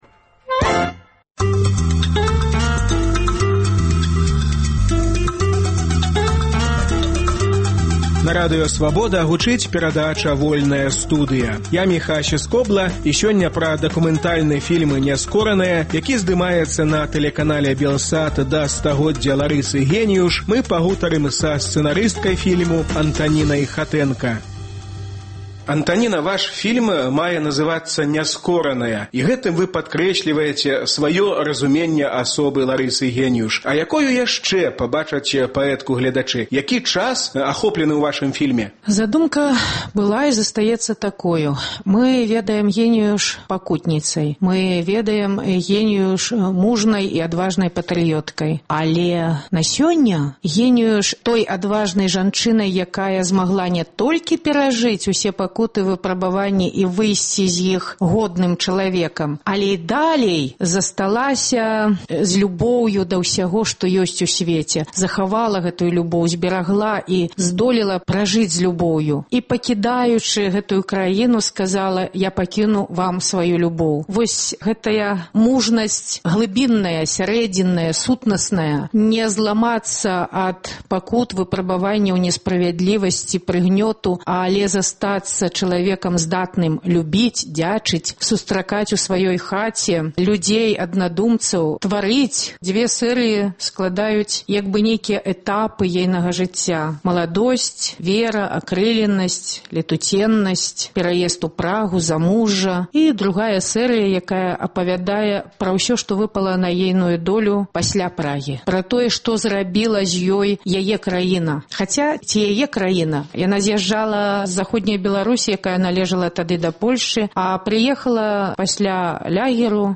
"Нескароная": дакумэнтальны фільм пра Ларысу Геніюш. Гутарка